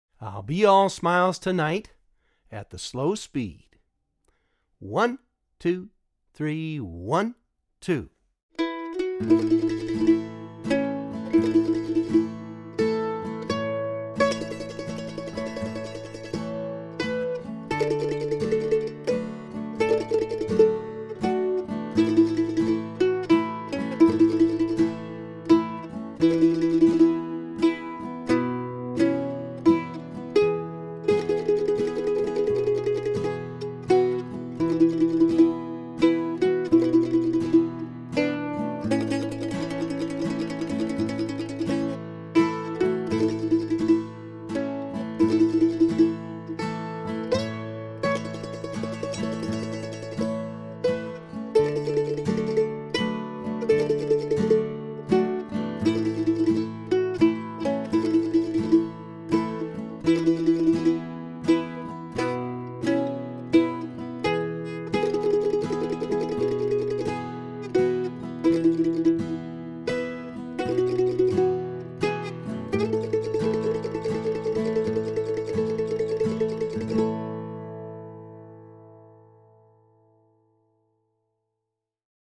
DIGITAL SHEET MUSIC - MANDOLIN SOLO
Traditional Mandolin Solo
both slow and regular speed